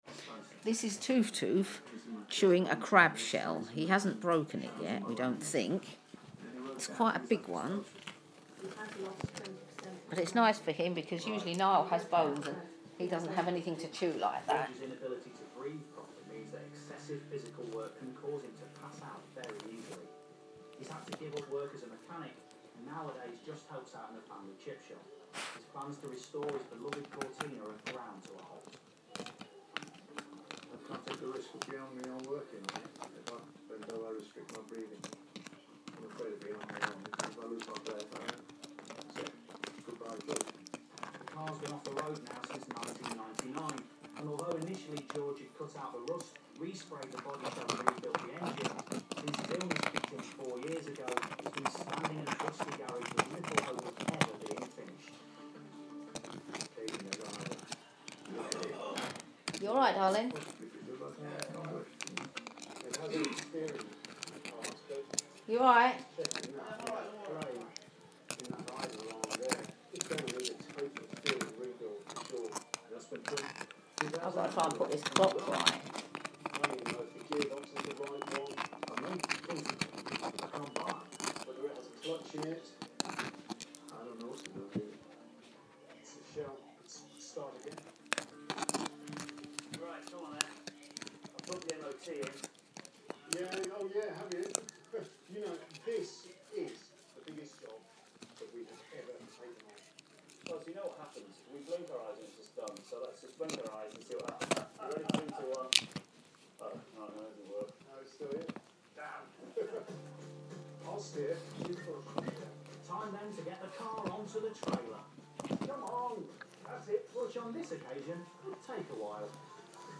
Toof Toof chewing on a crab shell